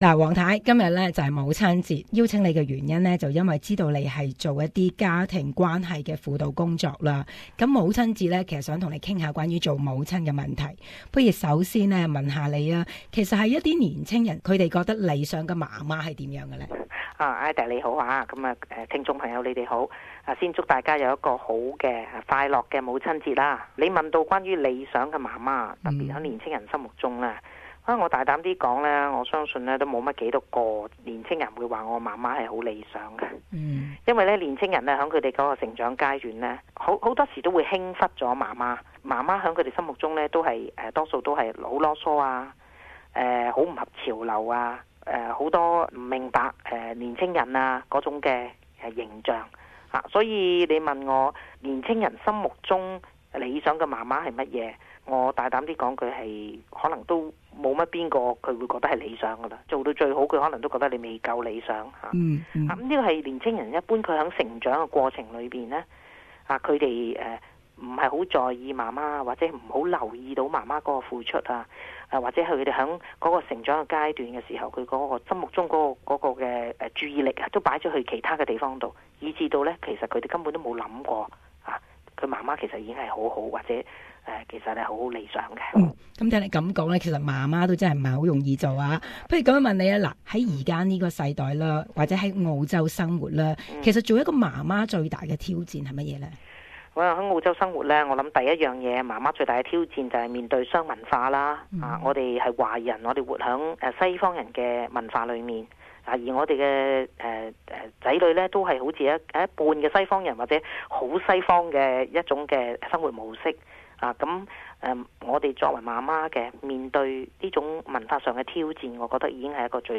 社区专访：母亲节专访